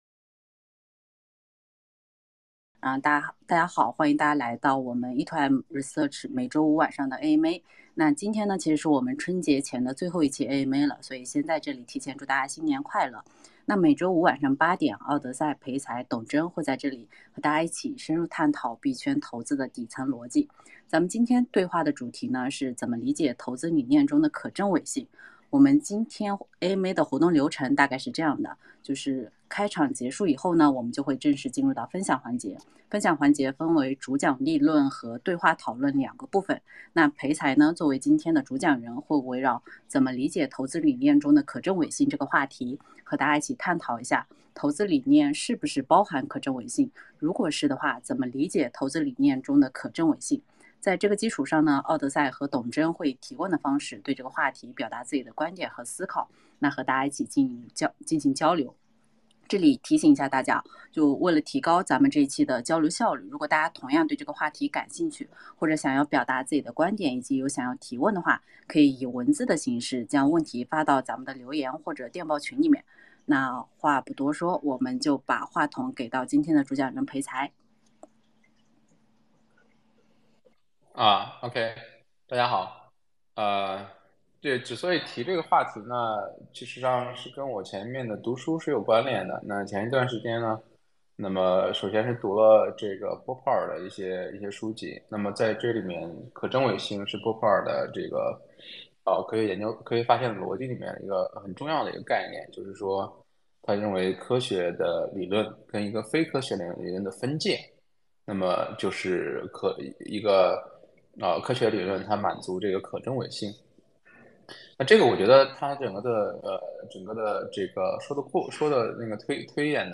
前言概述 本周 E2M research 三位主讲人一起探讨《怎么理解投资理念中的可证伪性？》 &nbsp